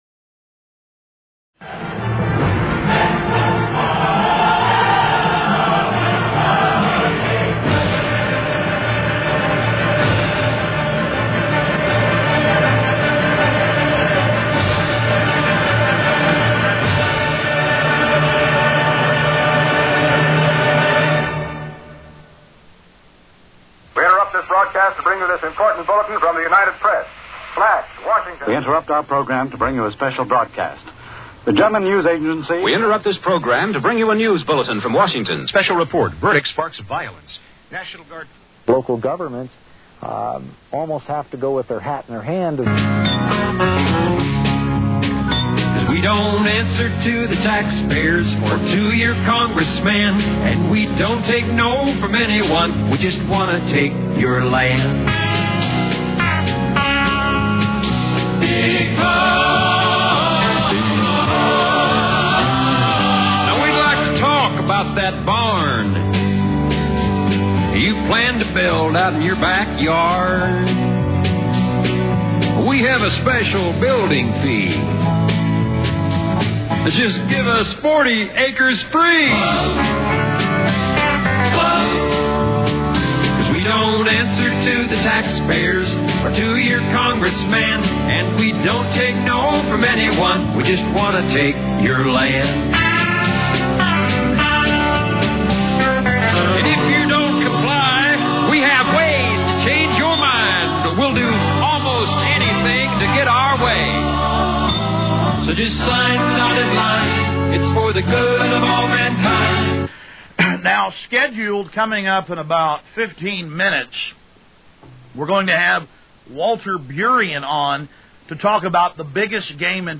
Alex Jones interviews